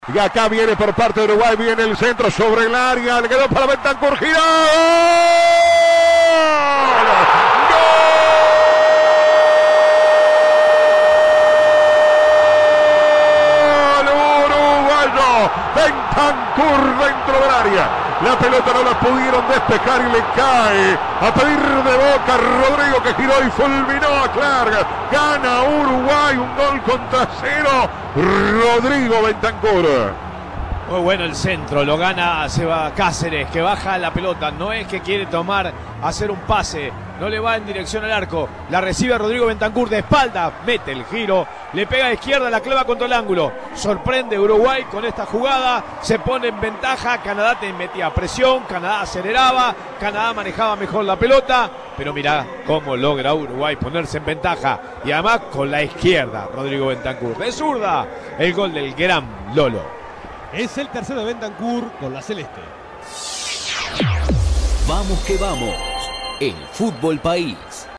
El 2-2 de la celeste en la voz del equipo de Vamos que Vamos